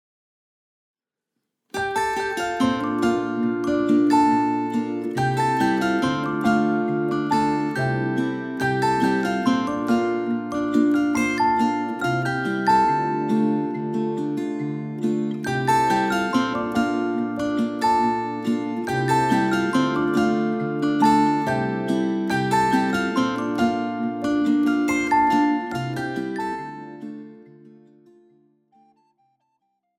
10 Popsongs für Altblockflöte
Besetzung: Altblockflöte